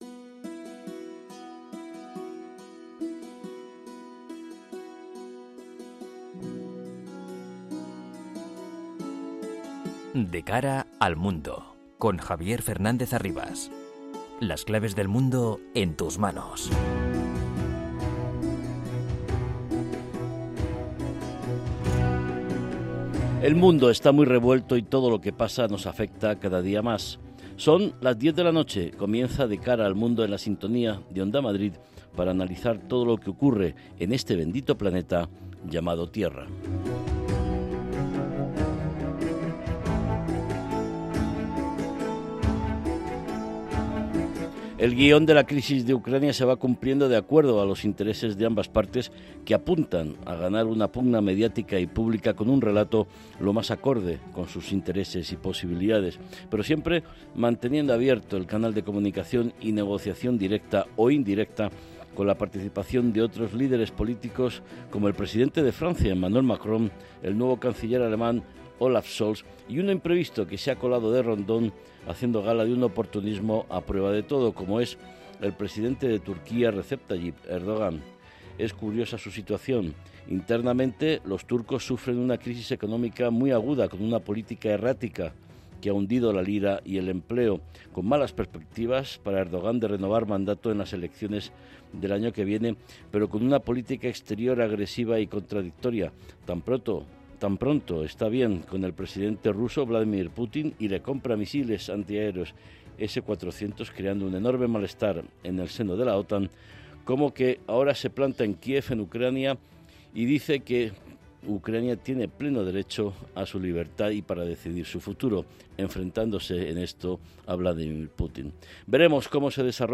con entrevistas a expertos y un panel completo de analistas